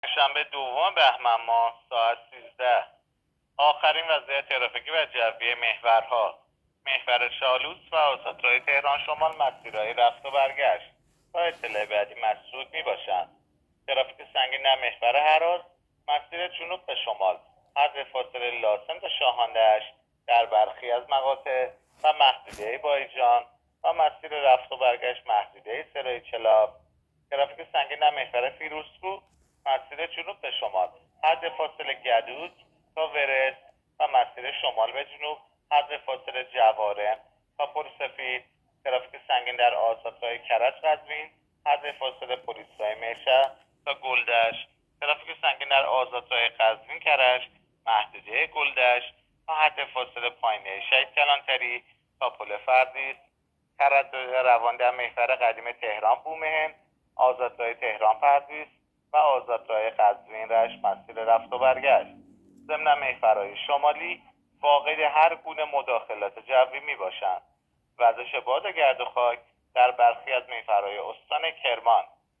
گزارش رادیو اینترنتی از آخرین وضعیت ترافیکی جاده‌ها ساعت ۱۳ دوم بهمن؛